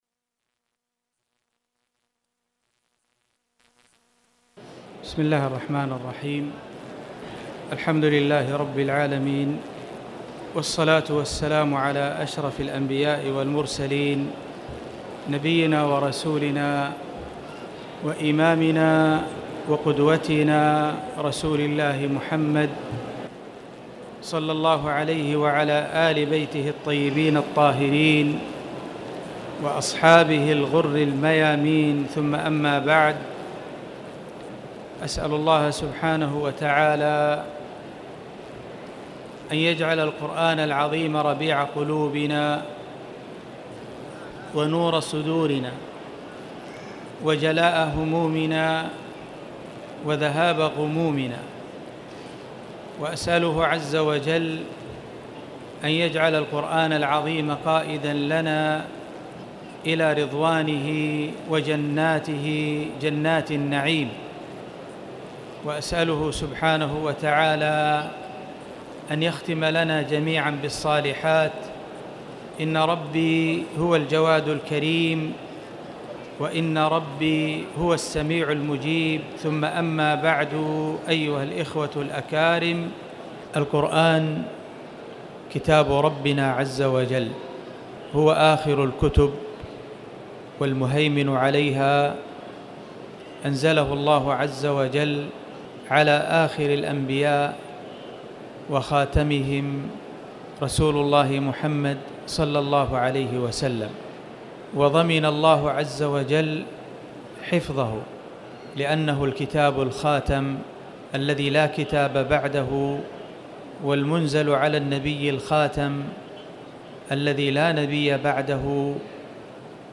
تاريخ النشر ١٠ صفر ١٤٣٩ هـ المكان: المسجد الحرام الشيخ